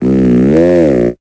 Cri de Chelours dans Pokémon Épée et Bouclier.